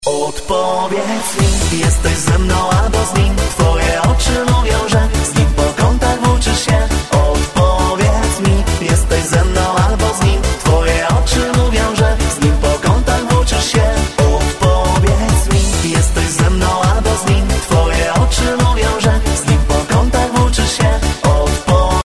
Disco polo